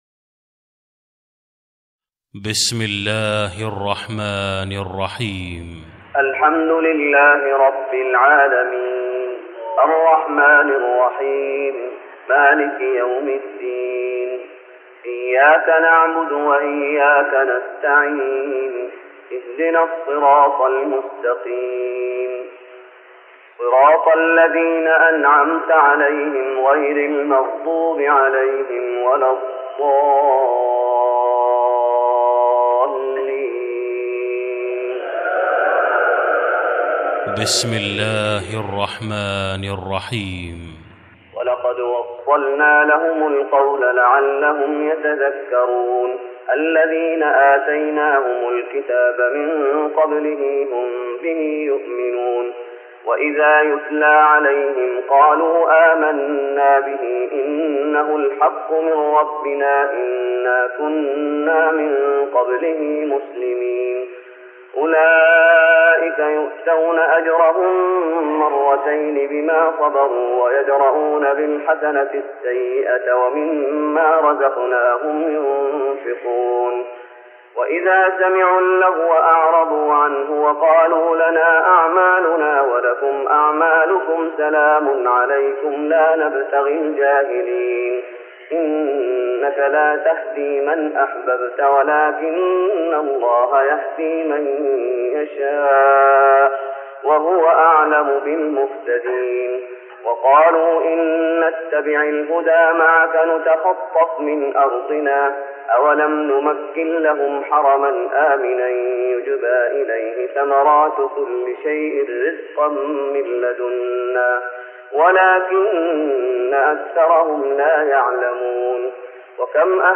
تراويح رمضان 1414هـ من سورة القصص (51-88) Taraweeh Ramadan 1414H from Surah Al-Qasas > تراويح الشيخ محمد أيوب بالنبوي 1414 🕌 > التراويح - تلاوات الحرمين